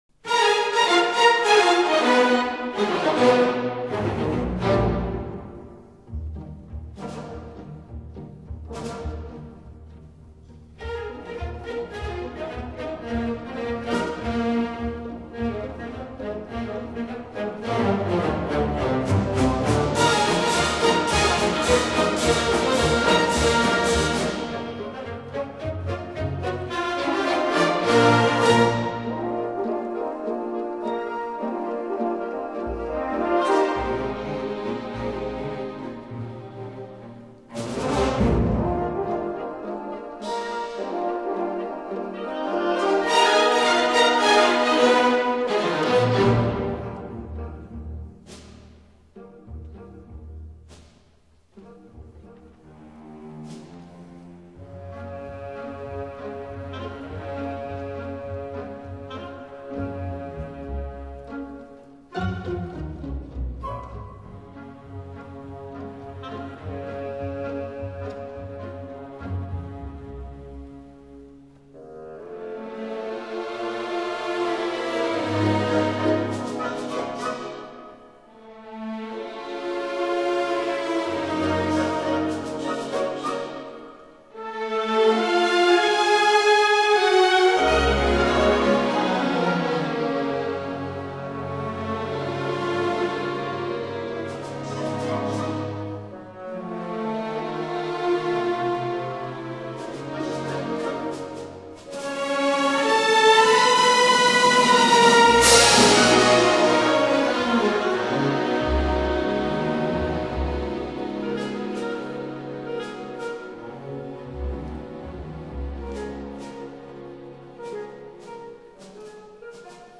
(這張是管弦演奏)